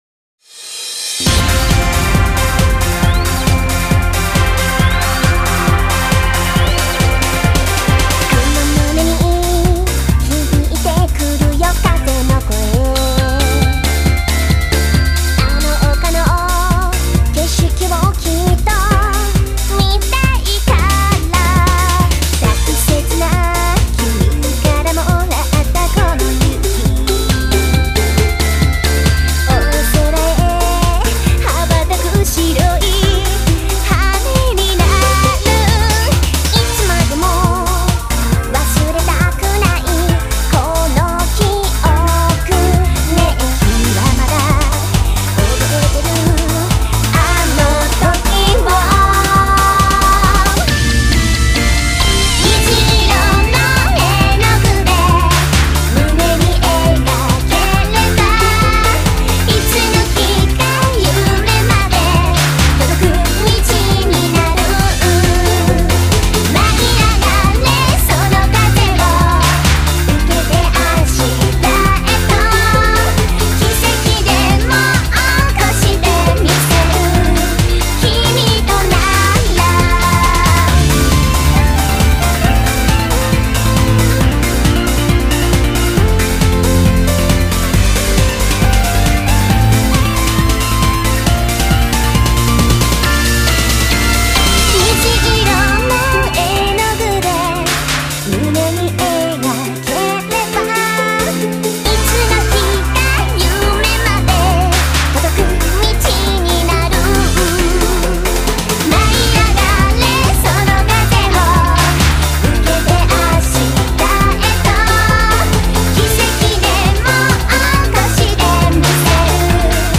◆◆　ボーカル曲　◆◆